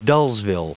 Prononciation du mot dullsville en anglais (fichier audio)
Prononciation du mot : dullsville